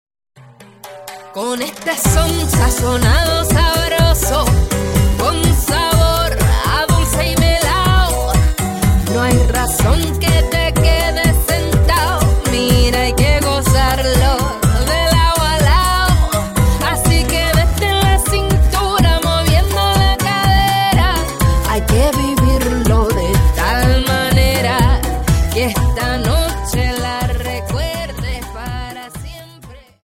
Dance: Cha Cha